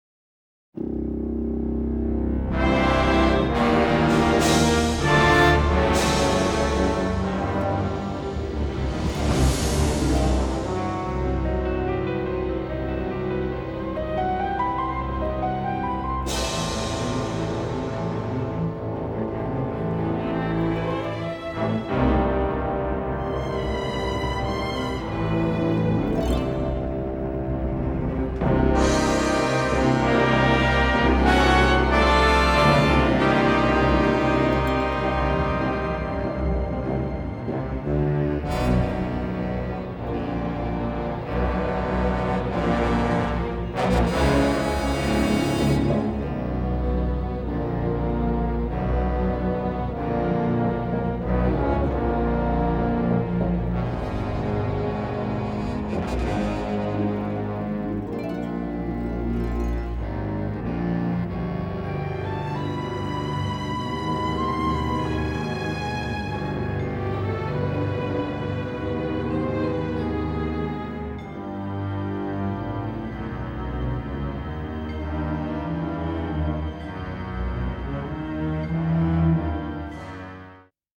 boisterous and colorful musical score
large orchestral adventure
powerful and quite thrilling
newly remastered by Digital Outland.